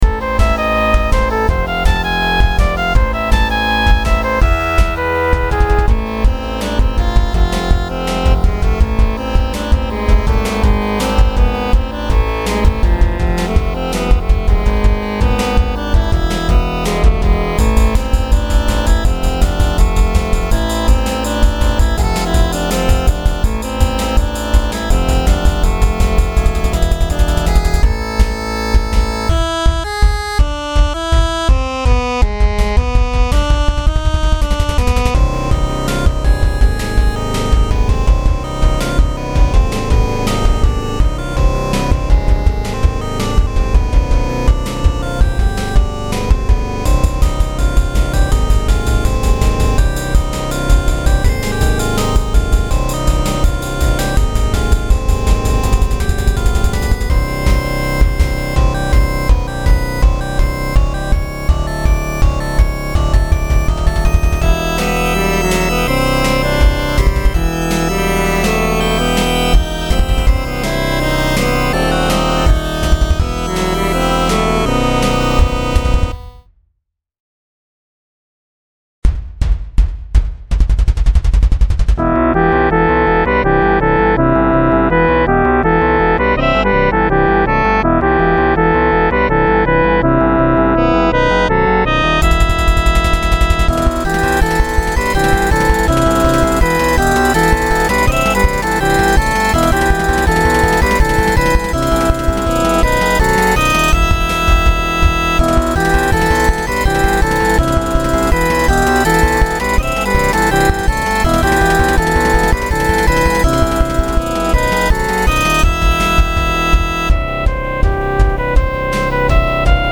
This is a Touhou-style piece I made in Jummbox that could theoretically be massaged to work on pmd mml. I used Markov chains to produce most of the chord progression.